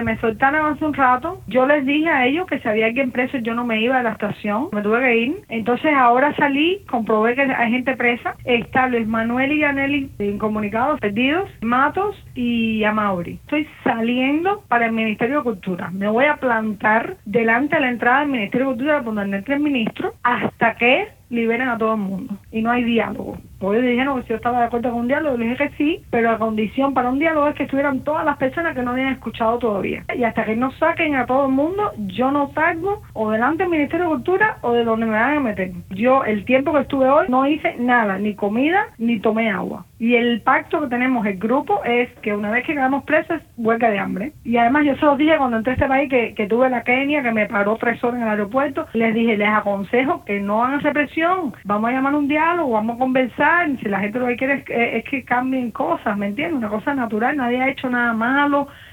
Declaraciones de Tania Bruguera